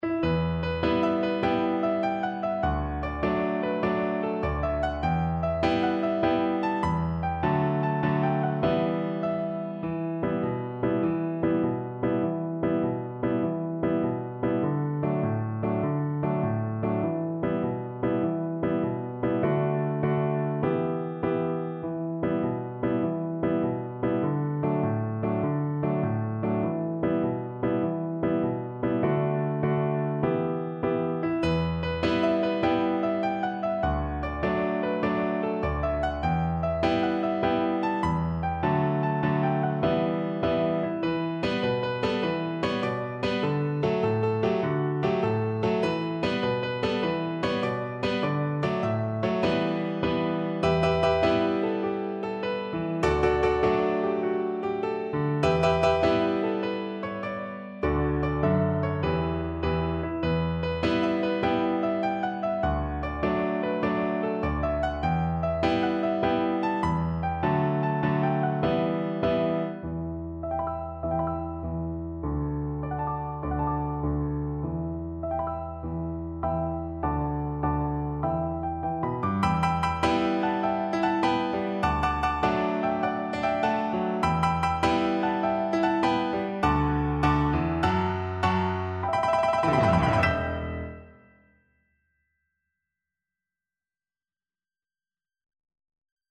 Play (or use space bar on your keyboard) Pause Music Playalong - Piano Accompaniment Playalong Band Accompaniment not yet available transpose reset tempo print settings full screen
Viola
Traditional Music of unknown author.
6/8 (View more 6/8 Music)
B minor (Sounding Pitch) (View more B minor Music for Viola )
Allegro .=c.100 (View more music marked Allegro)